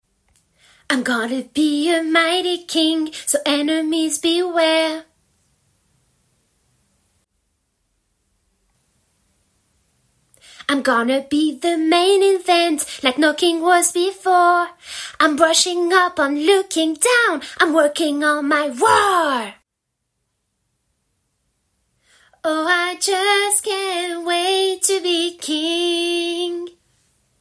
- Soprano